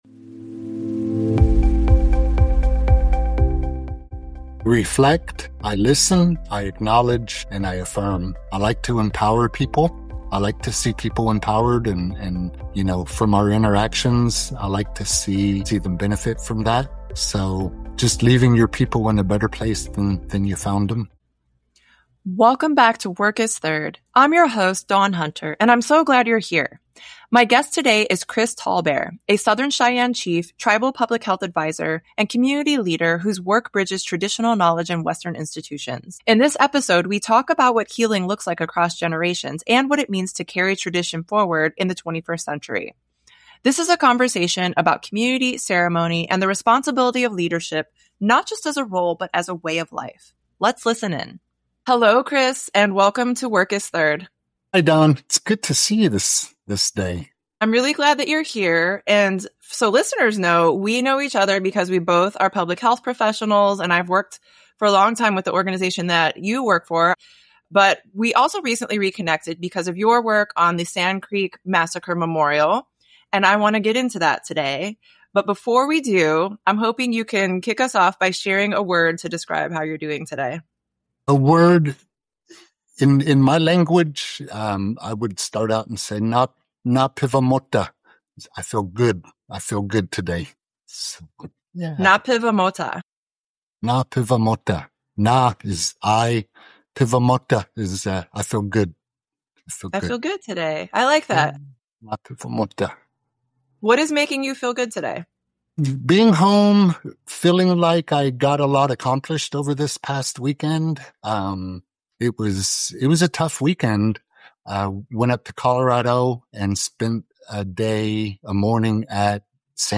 In this conversation, he shares his experience as a descendant of survivors of the Sand Creek Massacre and his role in memorialization efforts.